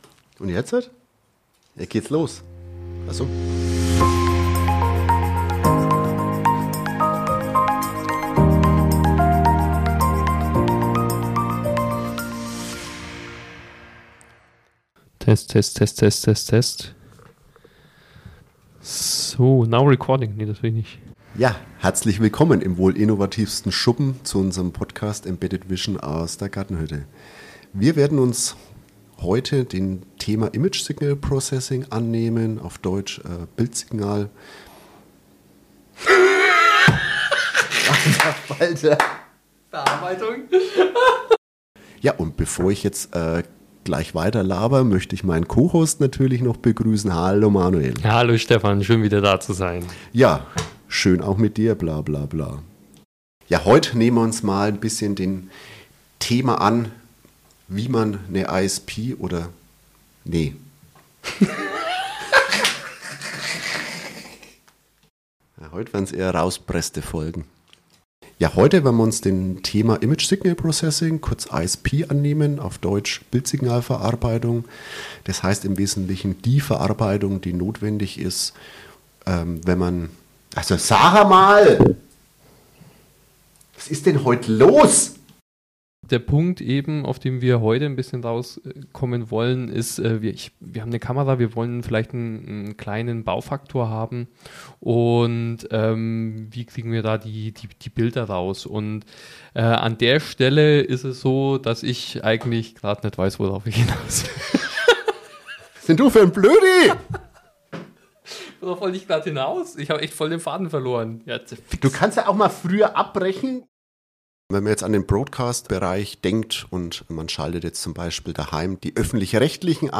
Outtakes 2024